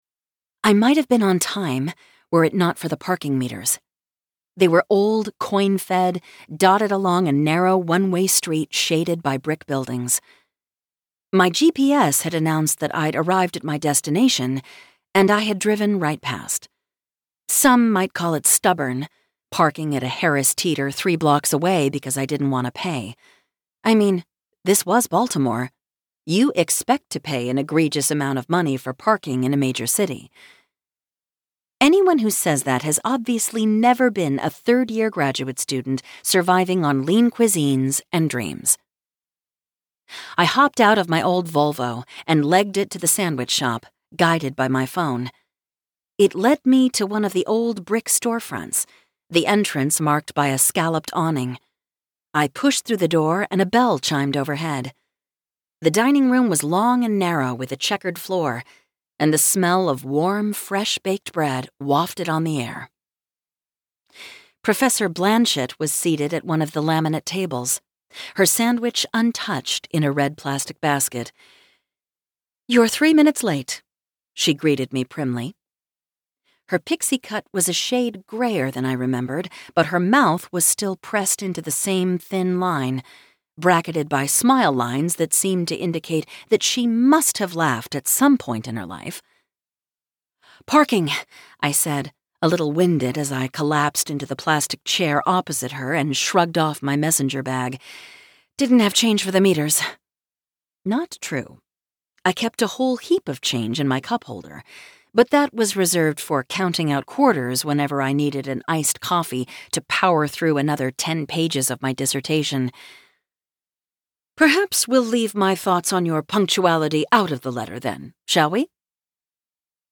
Related Audiobooks